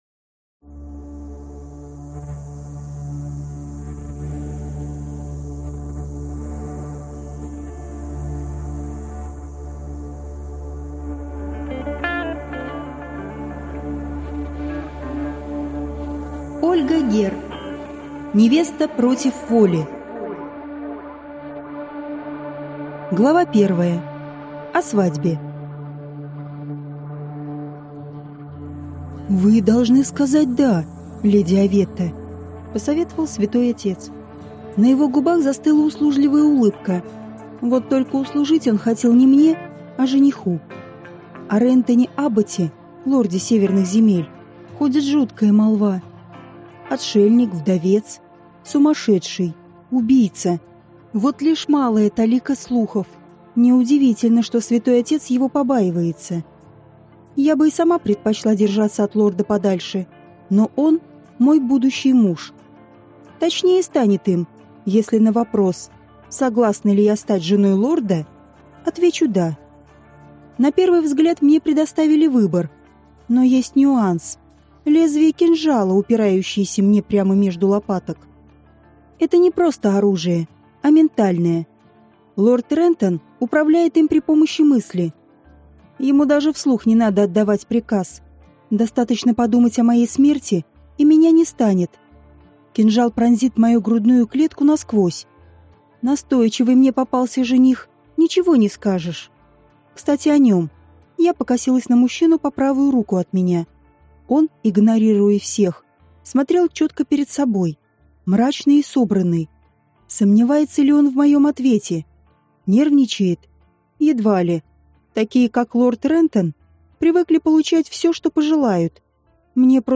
Aудиокнига Невеста против воли